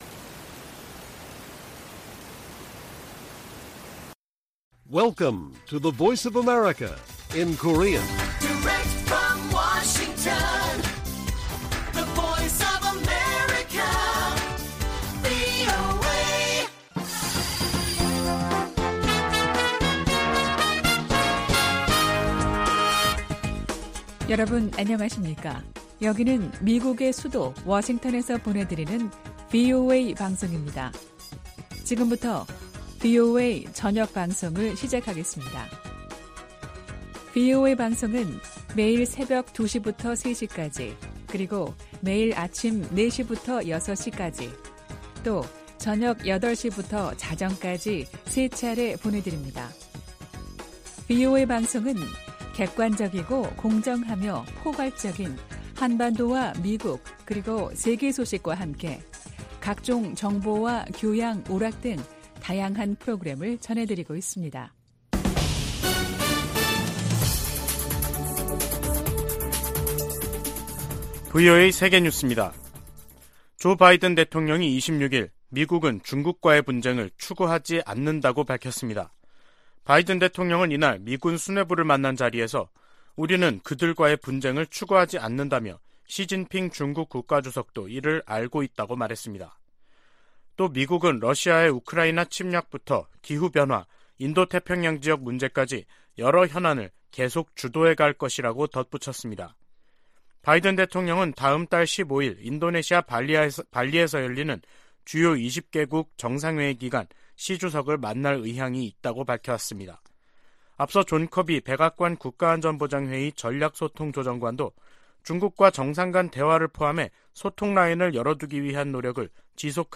VOA 한국어 간판 뉴스 프로그램 '뉴스 투데이', 2022년 10월 27일 1부 방송입니다. 북한이 7차 핵실험을 감행할 경우 연합훈련과 추가 제재 등 다양한 대응 방안이 있다고 미 국무부가 밝혔습니다. 한국 국가정보원은 북한이 미국의 11월 중간선거 이전까지 7차 핵실험을 할 가능성이 있다는 기존의 정보분석을 거듭 제시했습니다. 2023 회계연도 국방수권법안에 대한 미국 상원 본회의 심의가 시작됐습니다.